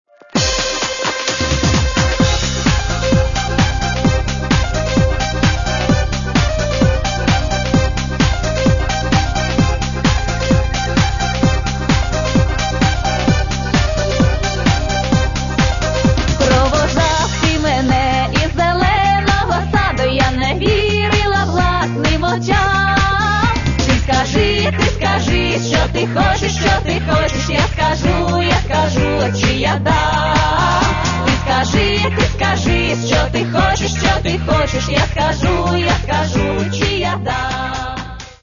Каталог -> Народна -> Сучасні обробки